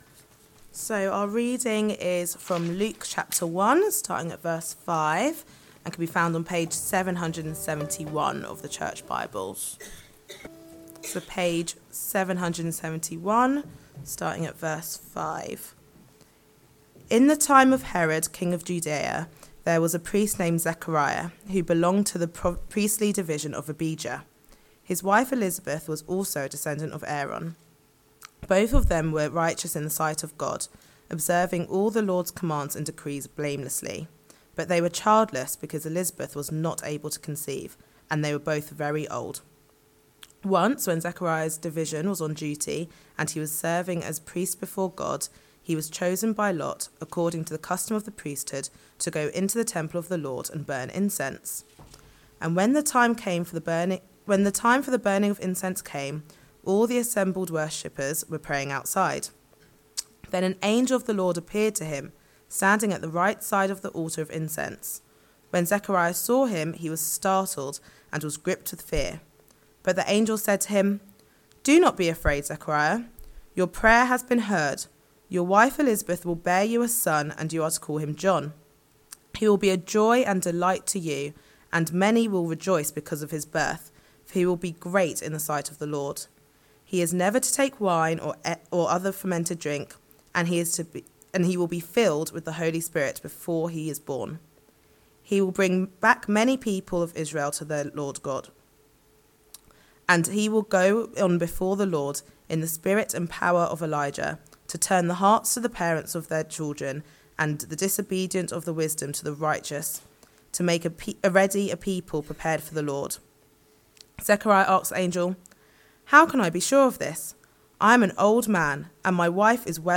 This is the second sermon in our 'Fulfilled?' sermon series looking at Luke 1-4.